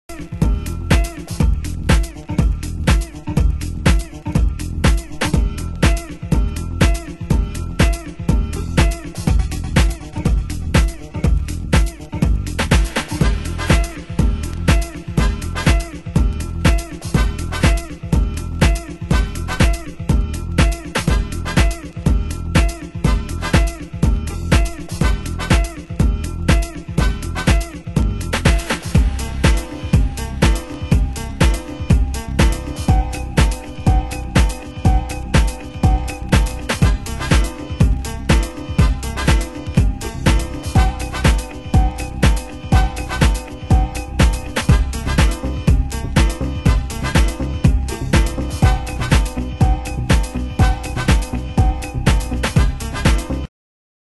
盤質：少しチリノイズ有